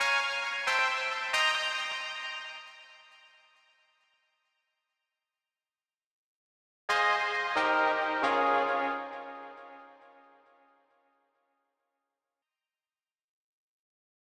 KIN Stab Riff Db-C-Eb-Ab.wav